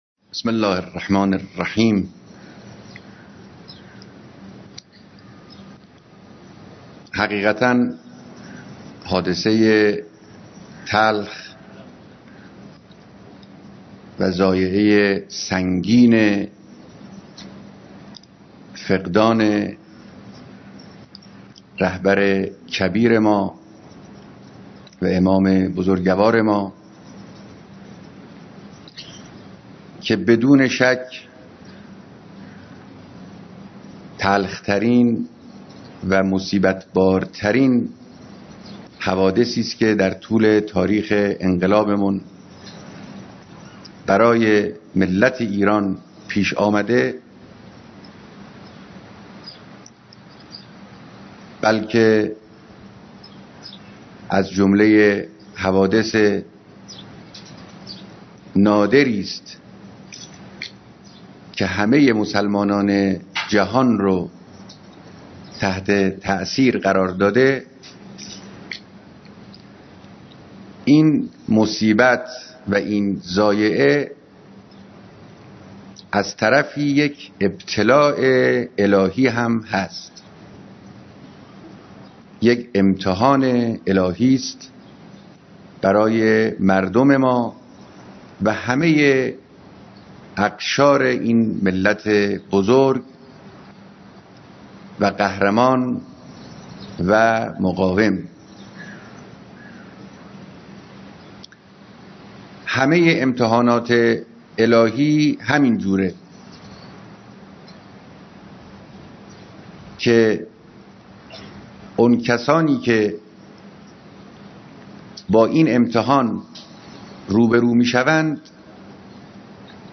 بیانات در مراسم بیعت معلمان و دانش آموزان